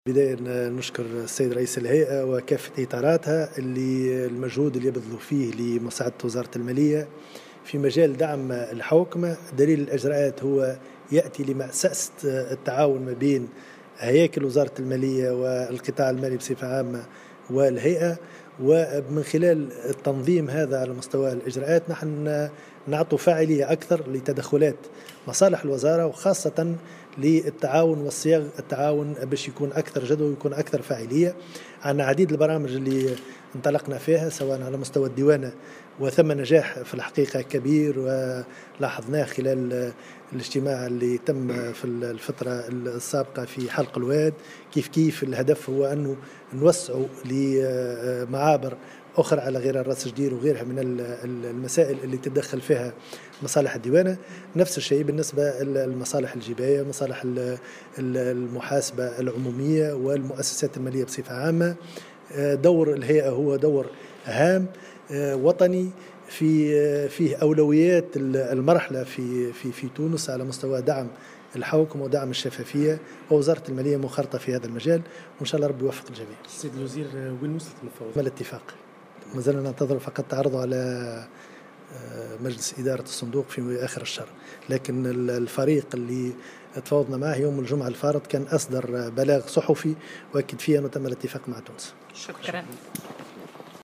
قال وزير المالية رضا شلغوم في تصريح لمراسل الجوهرة "اف ام" اليوم على هامش اشرافه على تفعيل اتفاقية تعاون وشراكة بين الهيئة الوطنية لمكافحة الفساد و وزارة المالية إن تم الاتفاق مع صندوق النقد الدولي بعد مفاوضات طويلة على صرف القسط الرابع من القرض المسند لتونس.